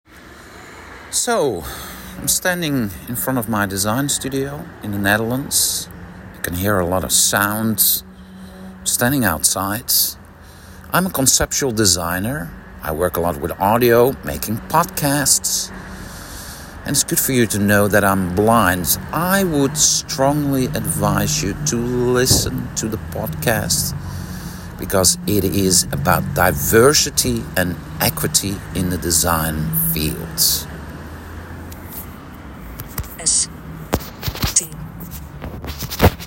[upbeat music with acoustic guitar and hand drums, many voices talking in the background]
[harmonizing voices sing as melody continues]